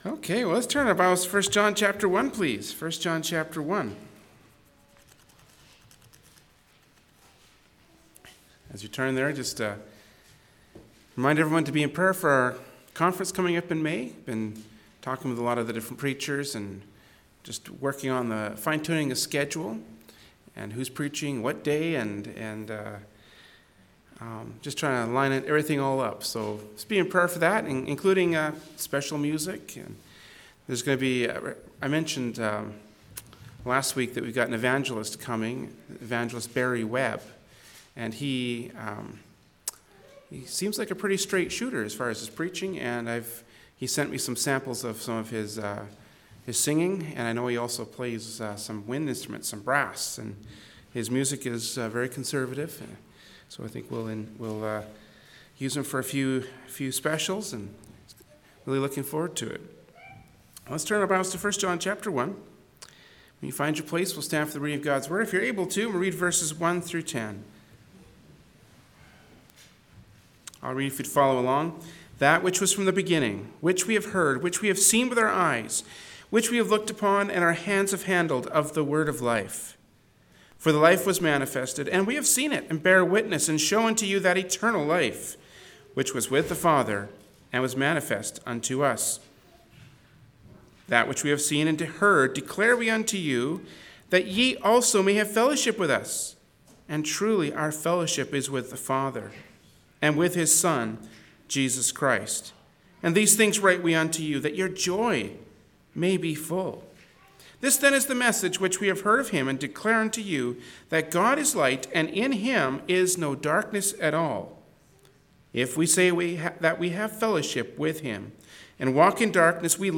Genre: Preaching.
1st John 1:1-10 Service Type: Wednesday Evening Service “1st John 1:1-10” from Wednesday Evening Service by Berean Baptist Church.